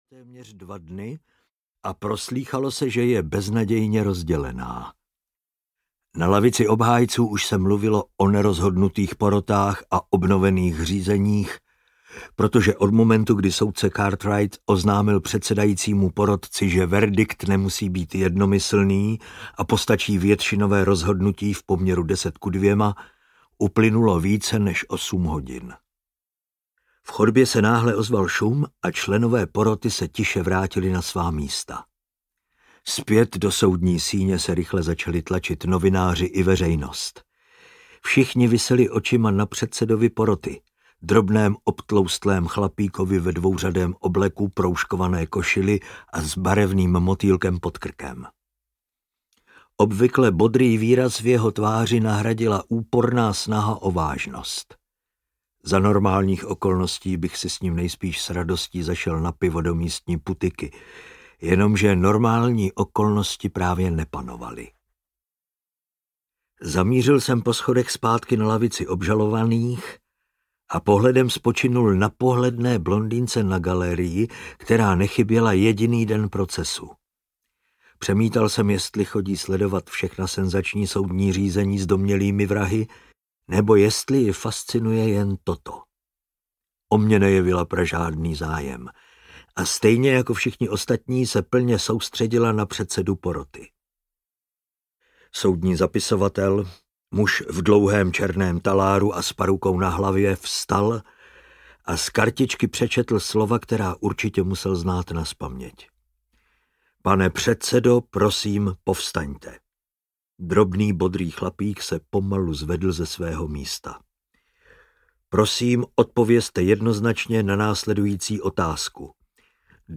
Dvanáct falešných stop audiokniha
Ukázka z knihy